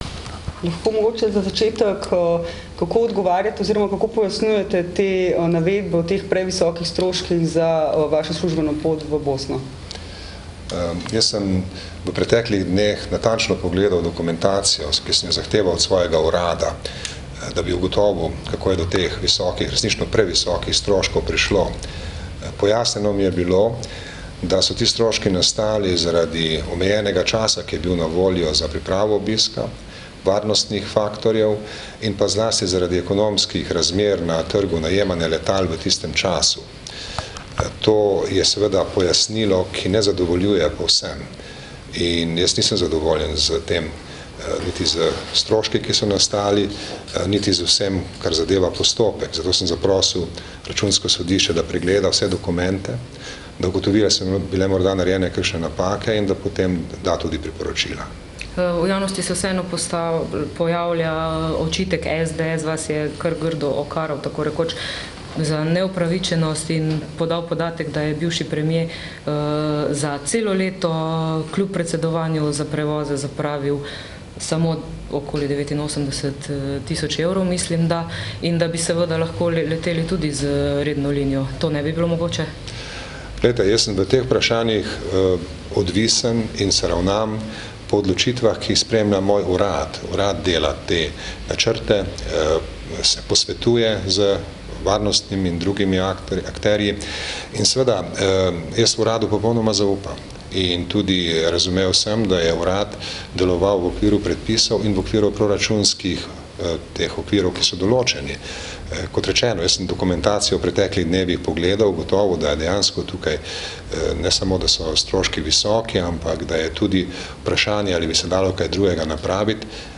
Zvočni zapis odgovorov predsednika republike na novinarska vprašanja
izjava_predsednika_o_najemu_letal.mp3